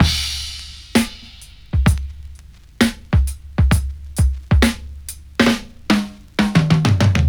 • 96 Bpm 2000s Drum Beat D# Key.wav
Free breakbeat - kick tuned to the D# note. Loudest frequency: 1144Hz